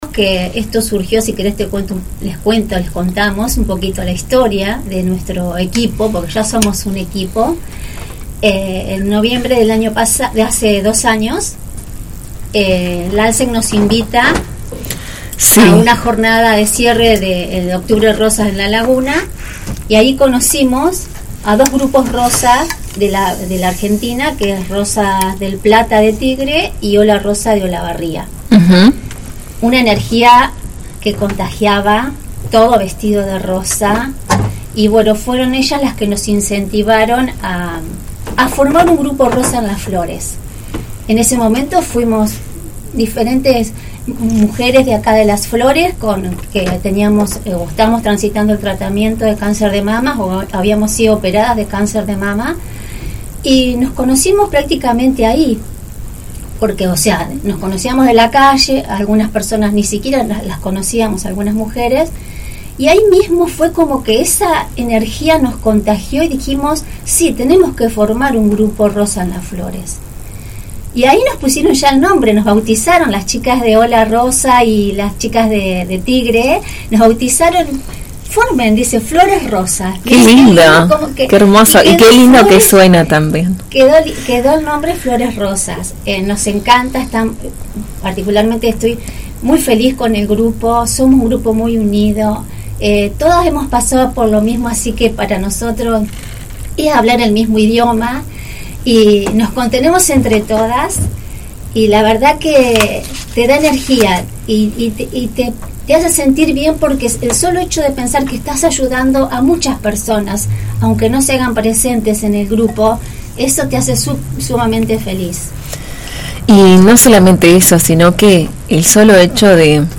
Juntas, visitaron la 91.5 y nos contaron sus proyectos y la movida de concientización que realizan durante todo el año.